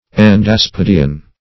Search Result for " endaspidean" : The Collaborative International Dictionary of English v.0.48: Endaspidean \En`das*pid"e*an\, a. [Endo- + Gr.